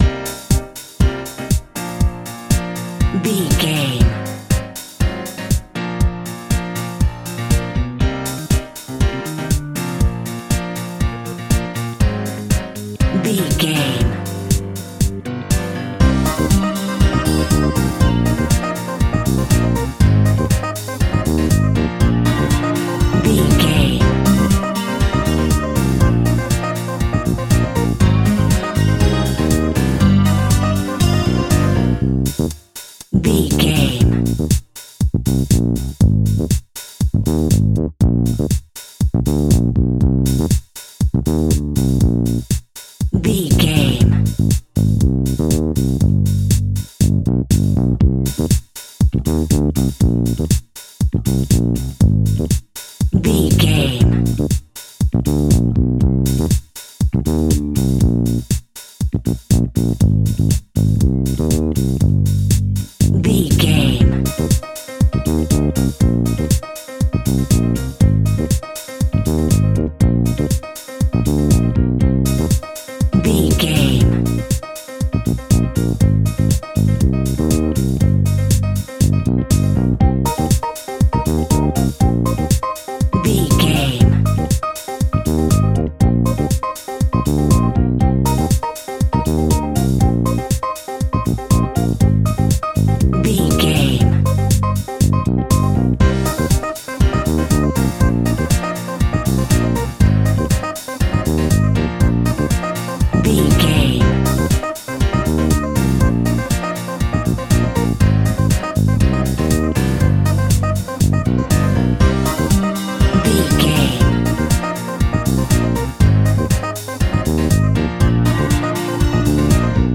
Aeolian/Minor
drums
bass guitar
brass
saxophone
trumpet